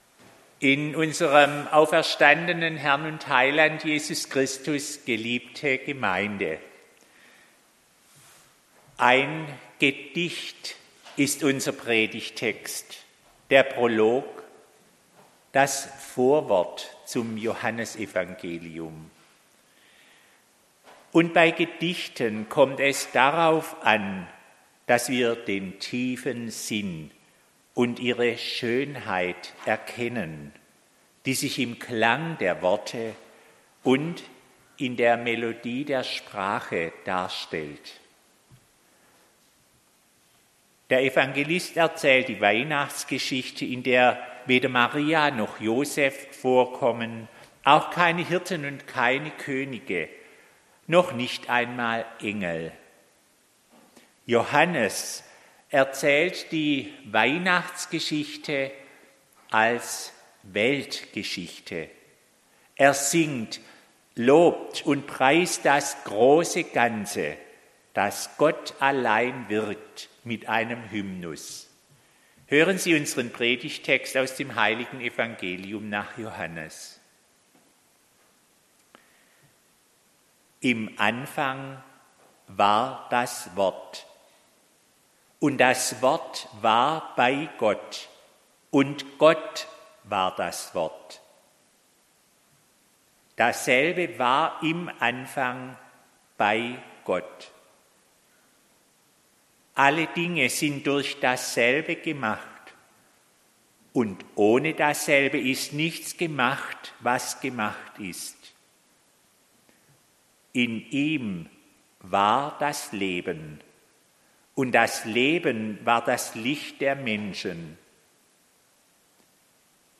Predigt
am 2. Weihnachtsfeiertag.